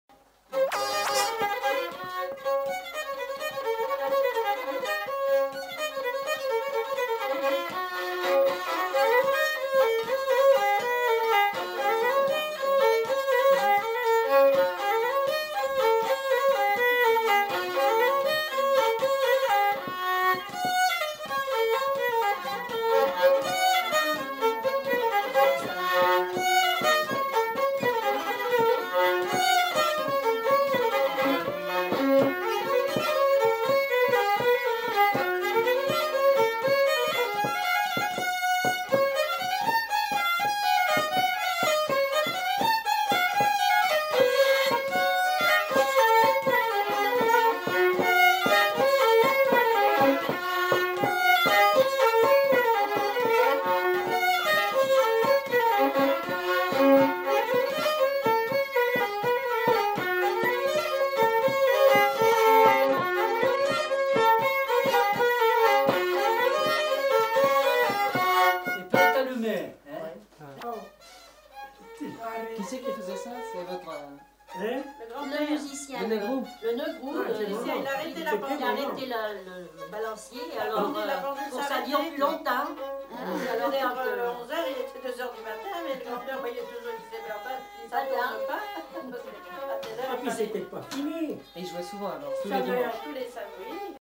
Aire culturelle : Limousin
Lieu : Lacombe (lieu-dit)
Genre : morceau instrumental
Instrument de musique : violon
Danse : valse
Notes consultables : Le second violon est joué par un des enquêteurs.